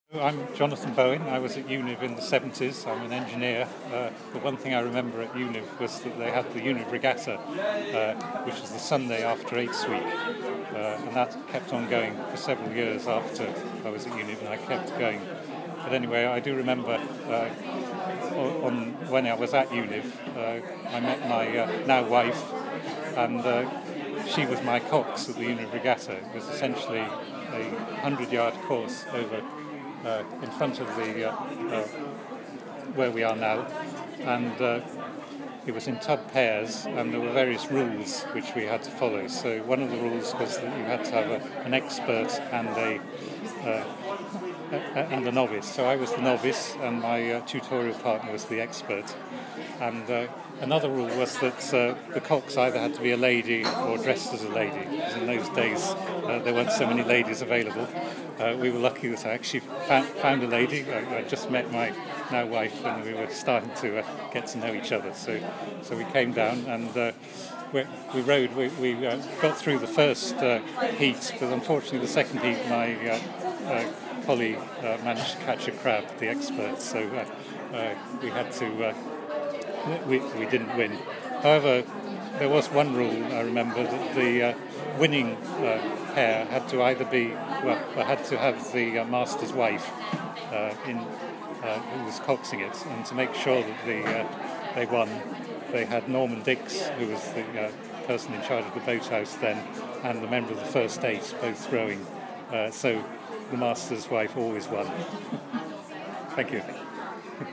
Interview
at Summer Eights 2013